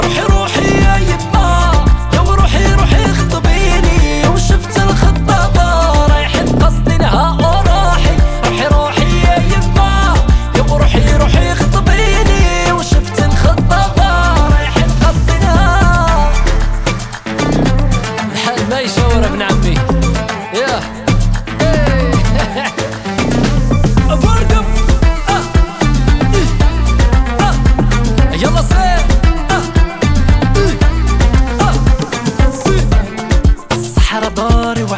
Жанр: Поп
# Arabic Pop